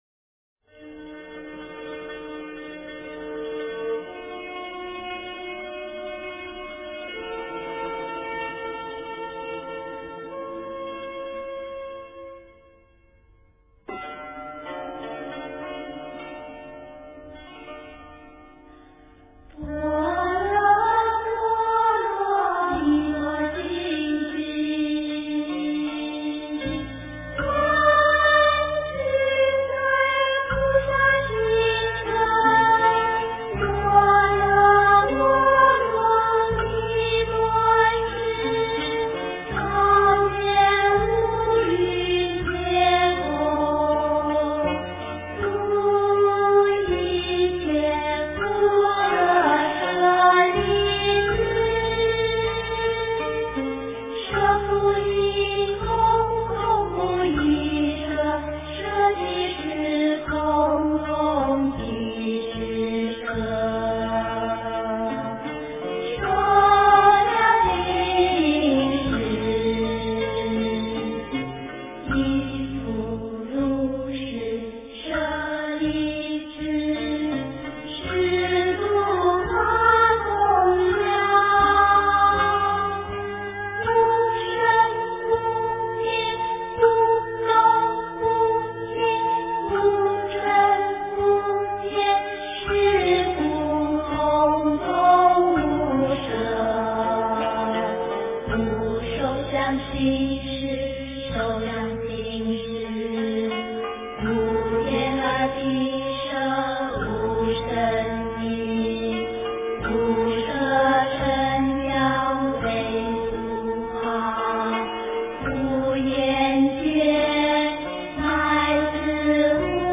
般若波罗蜜多心经 诵经 般若波罗蜜多心经--童音 点我： 标签: 佛音 诵经 佛教音乐 返回列表 上一篇： 般若波罗蜜多心经 下一篇： 大悲咒 相关文章 炉香赞 炉香赞--如是我闻...